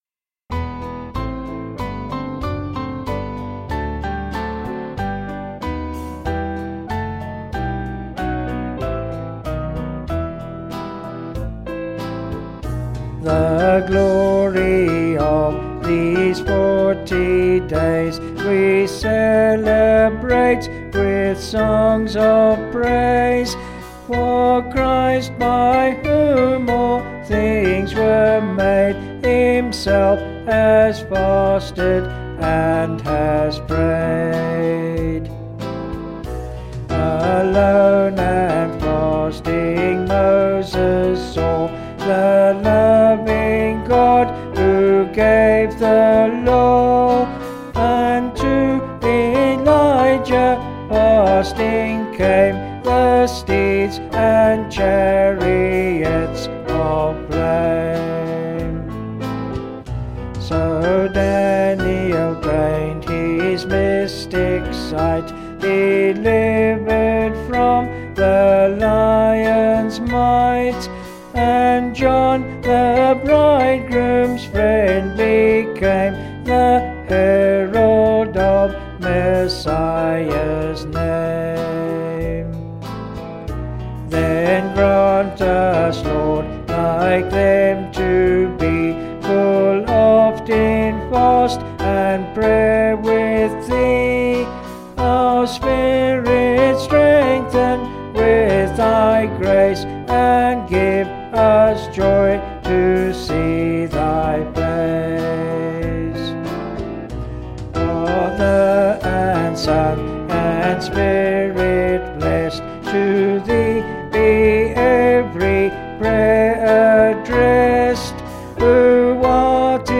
5/Em
Vocals and Band